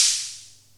010-HH_DrumHits_OpenHat09.wav